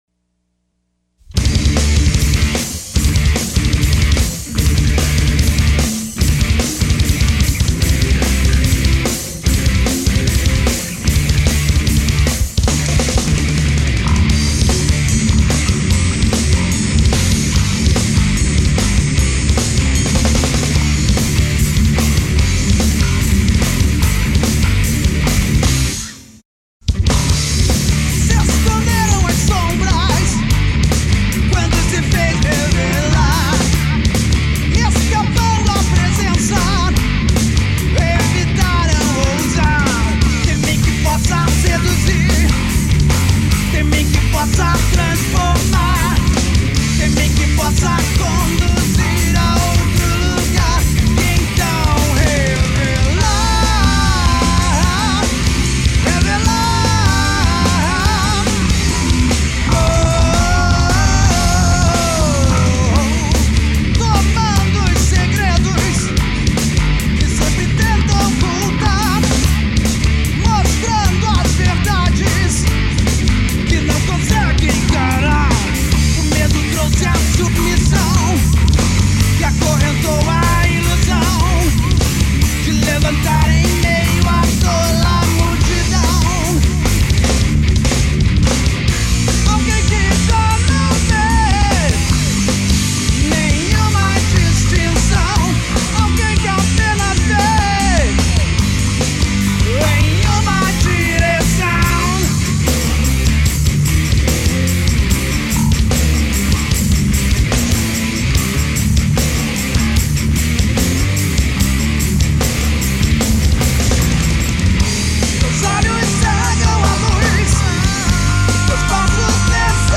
baixo
vocais
guitarra
bateria